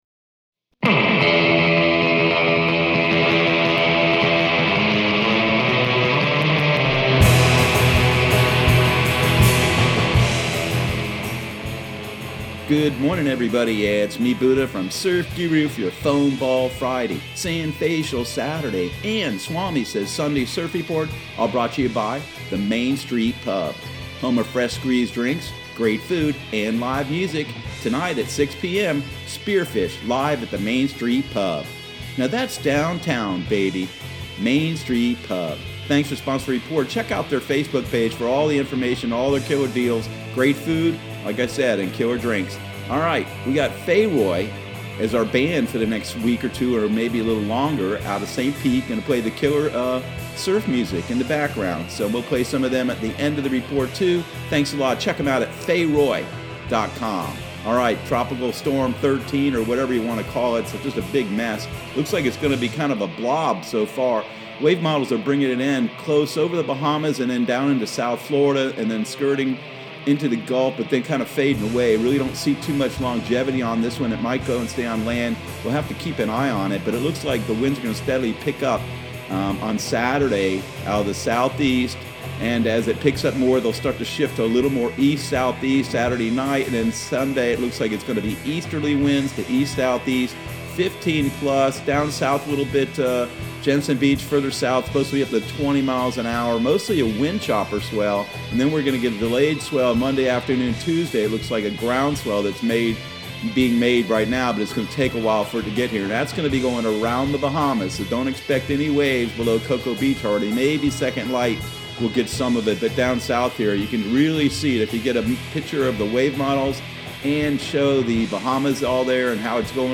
Surf Guru Surf Report and Forecast 08/21/2020 Audio surf report and surf forecast on August 21 for Central Florida and the Southeast.